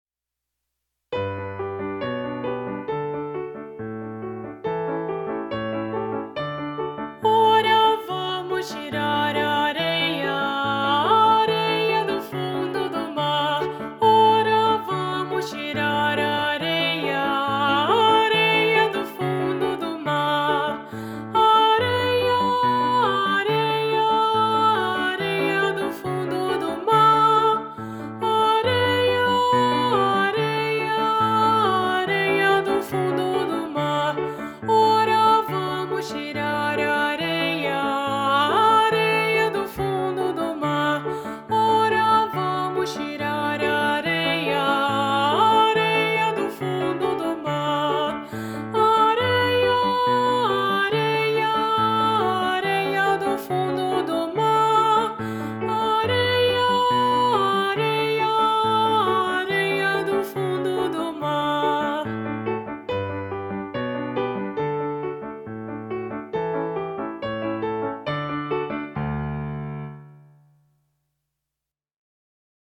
Voz Guia